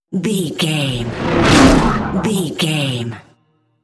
Whoosh fast airy cinematic
Sound Effects
Fast
whoosh